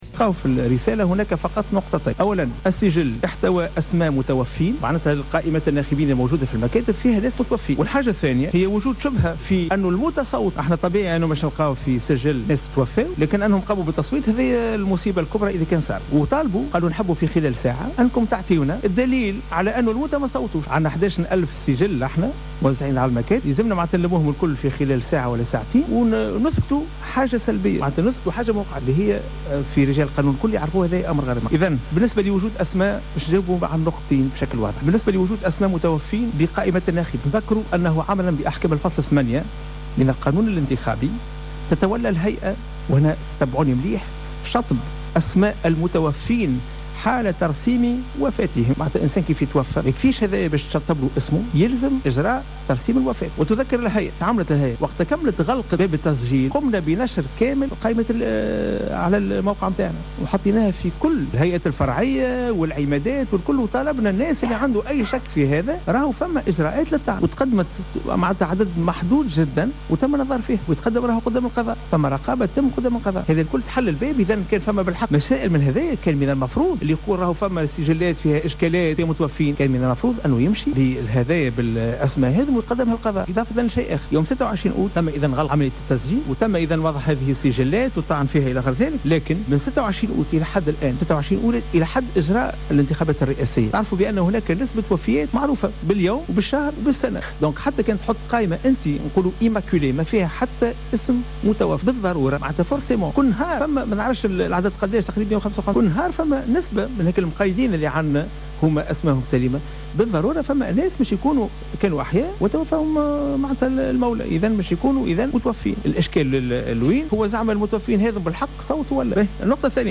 أكد رئيس الهيئة العليا المستقلة للانتخابات شفيق صرصار اليوم خلال ندوة صحفية أنه لم يثبت بالمرّة استعمال أسماء المتوفين من قبل بعض الناخبين خلال الانتخابات.